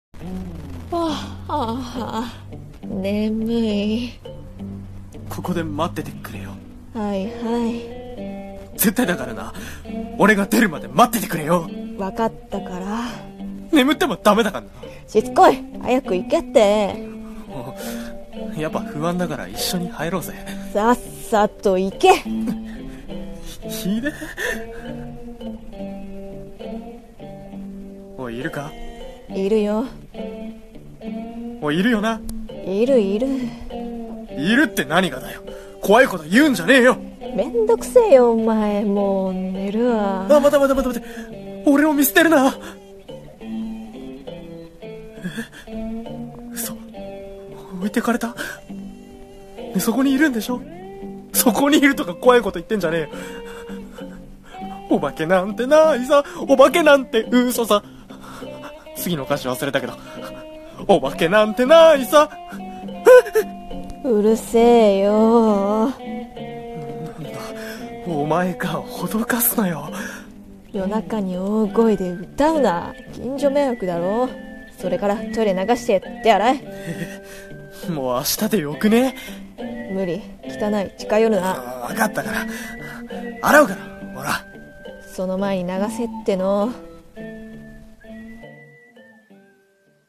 【ギャグ声劇台本】おばけなんてないさ